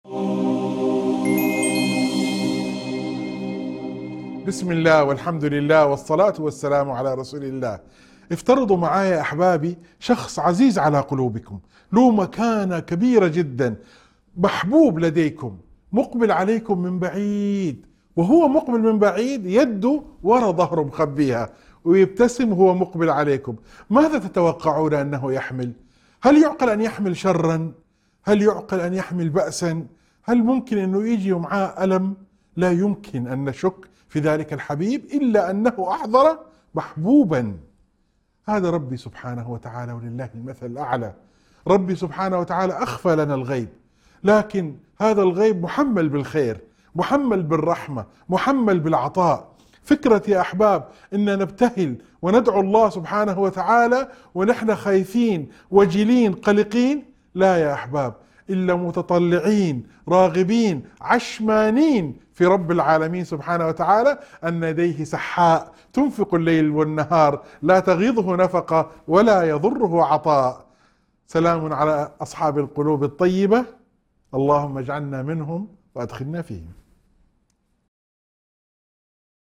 موعظة مؤثرة تشجع على حسن الظن بالله تعالى والتفاؤل بقدره، مهما بدت الأمور من بعيد. تذكر بأن الغيب عند الله محمّل بالخير والرحمة، وتحث على الدعاء مع الرجاء والثقة في عطاء الرب الكريم الذي لا ينفد.